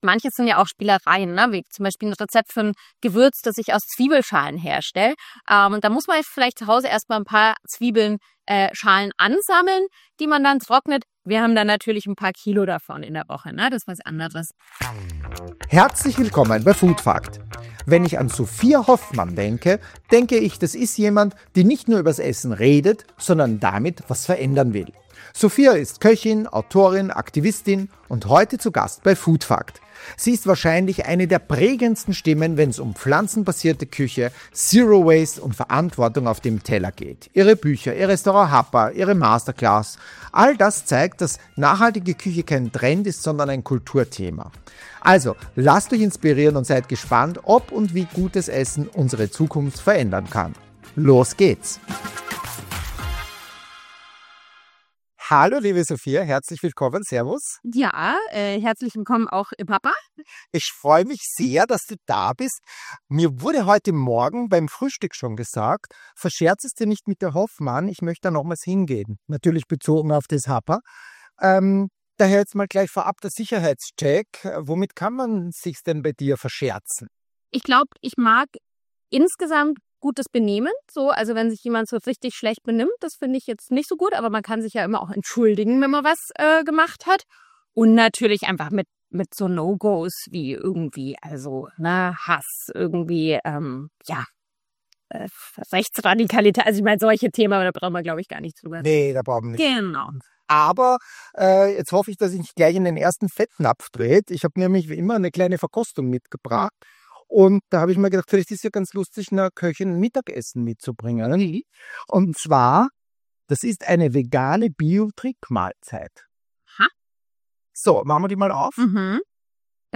Ein Gespräch über Mut, Geschmack – und die Kunst, Verantwortung köstlich zu machen.